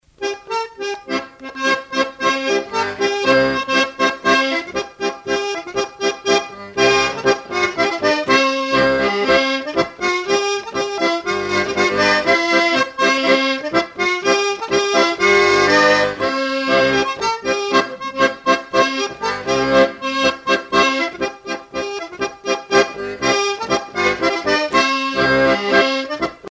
danse : polka des bébés ou badoise
Pièce musicale éditée